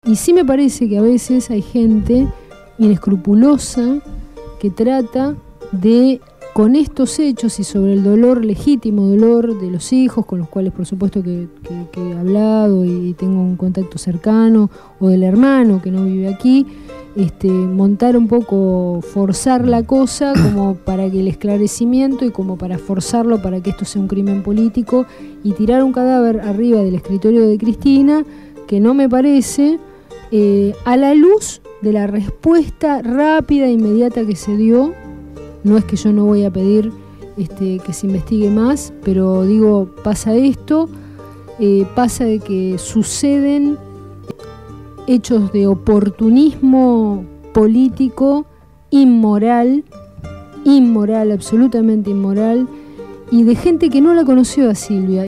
Presentamos tres fragmentos de la extensa charla que tuvo en los estudios de la radio en el programa Cambio y Futuro en el aire (jueves 20 a 22 horas).